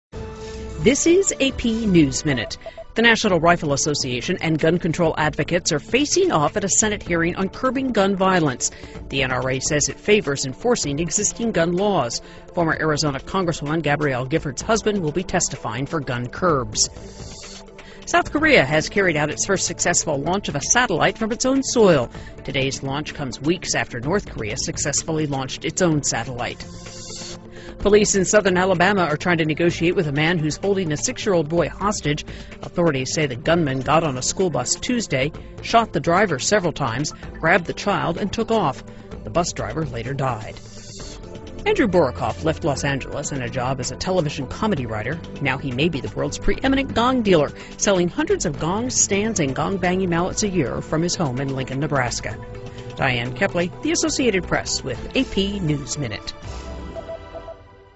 美联社新闻一分钟 AP 2013-02-06 听力文件下载—在线英语听力室